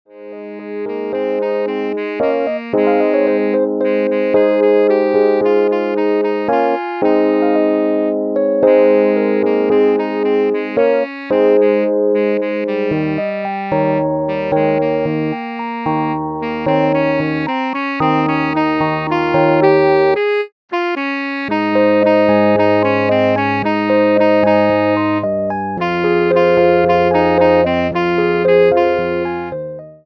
for Alto Sax & Keyboard